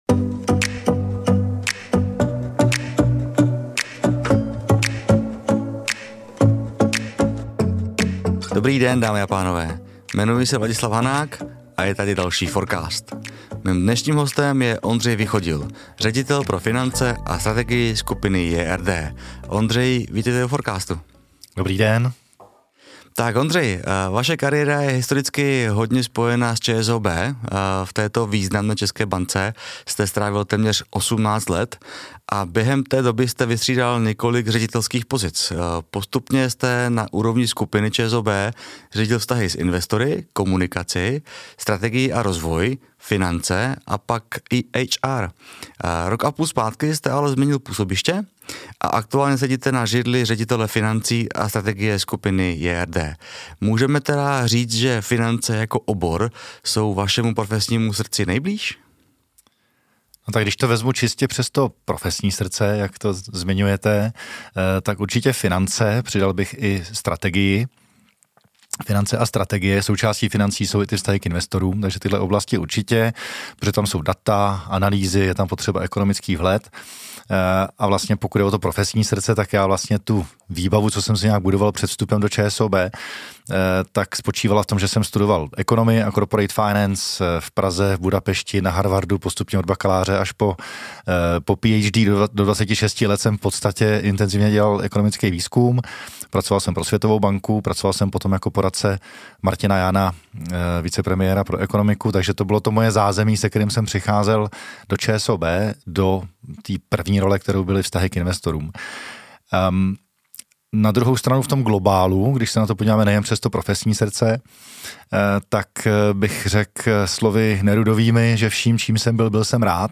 Mám za sebou další velmi zajímavé povídání ve studiu!